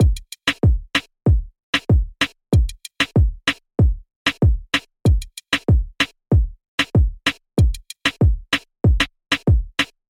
雷盖鼓
描述：雷鬼鼓
Tag: 160 bpm Reggae Loops Drum Loops 1.01 MB wav Key : Unknown FL Studio